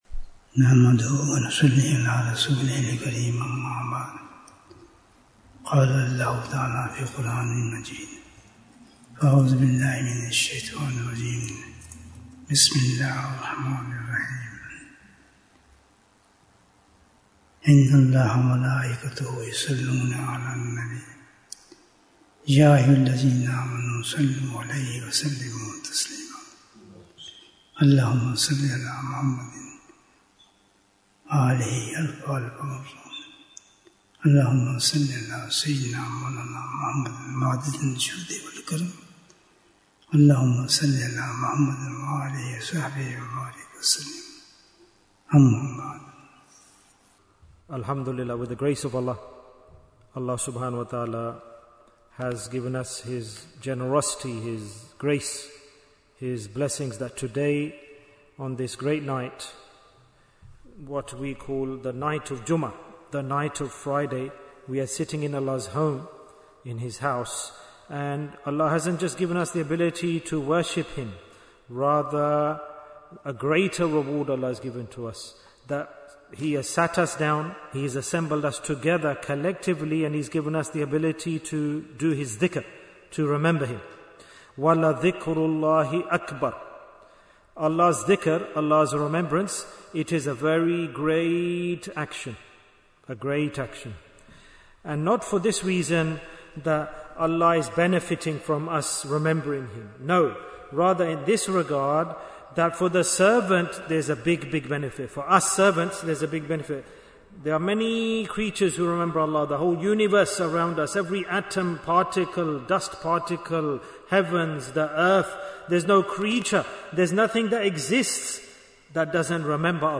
Which is a Deed of Jannah? Bayan, 50 minutes14th August, 2025